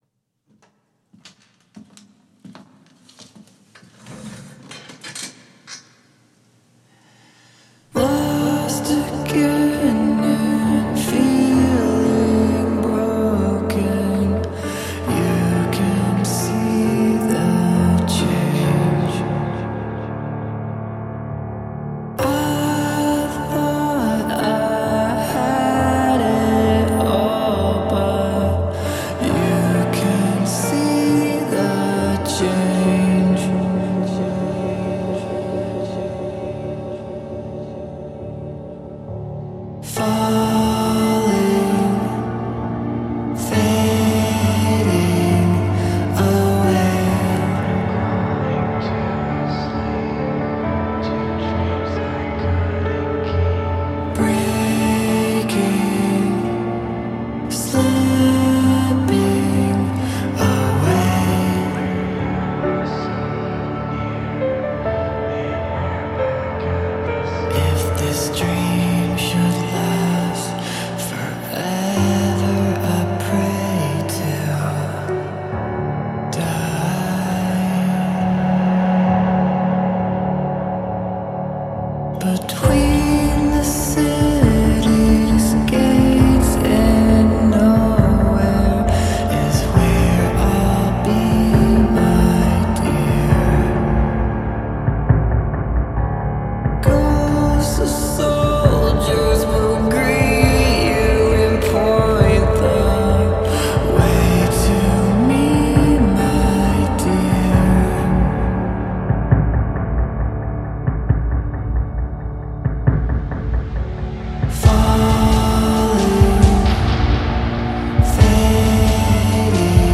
متال Metal